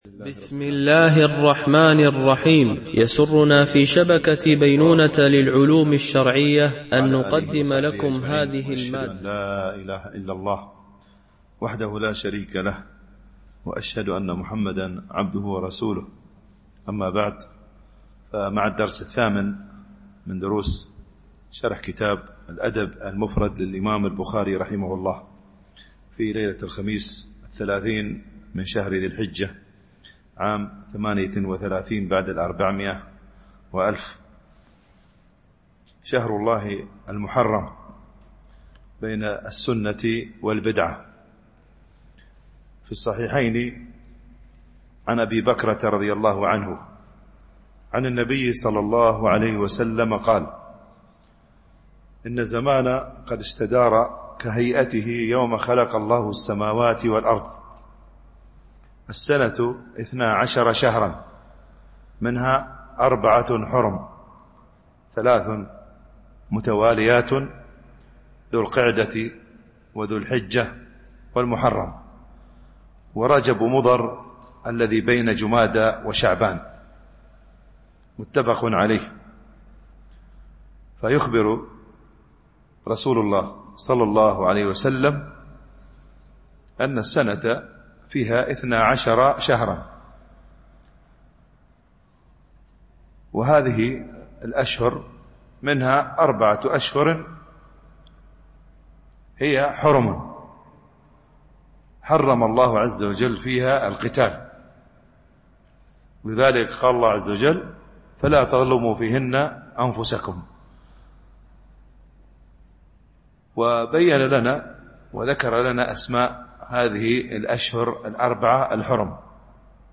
شرح الأدب المفرد للبخاري ـ الدرس 8 ( الحديث 34-39 )